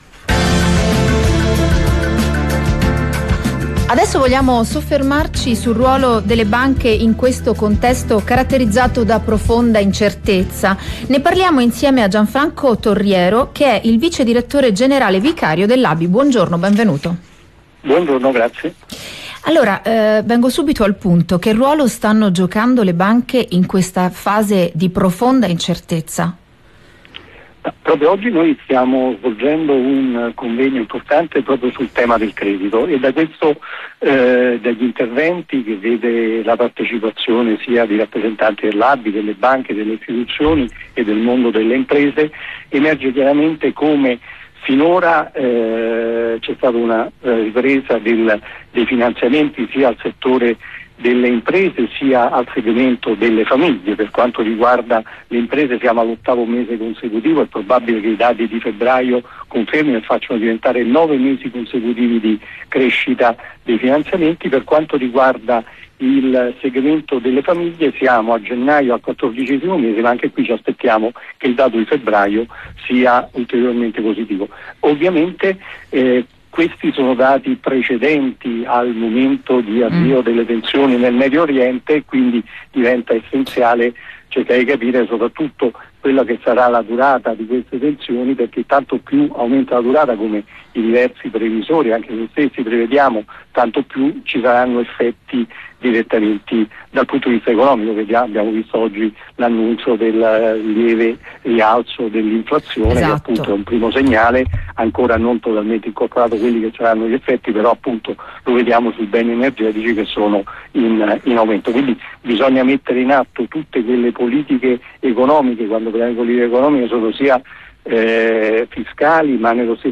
Intervista su Rai RadioUno